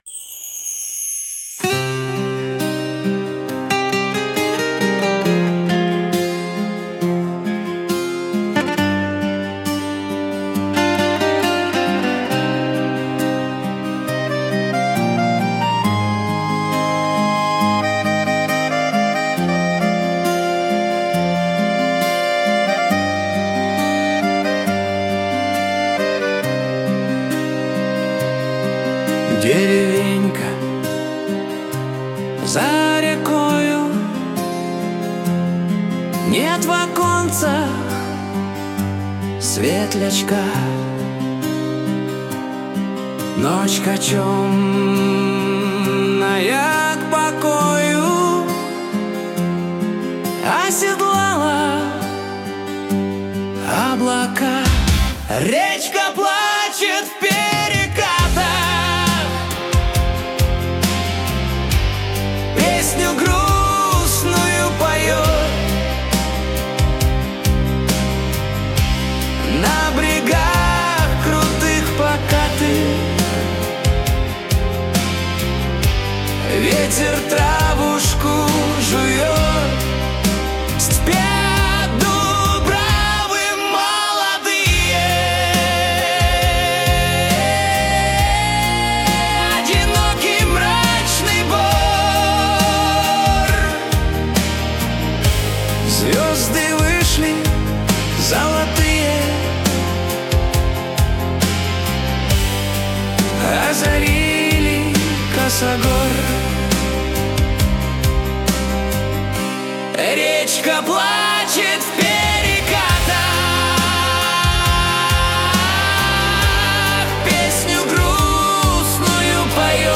Стихотворение в звуке МП-3 ДЕРЕВЕНЬКА В СНЕГУ слушать